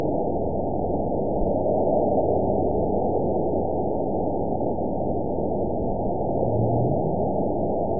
event 920473 date 03/27/24 time 05:10:15 GMT (1 year, 1 month ago) score 9.22 location TSS-AB02 detected by nrw target species NRW annotations +NRW Spectrogram: Frequency (kHz) vs. Time (s) audio not available .wav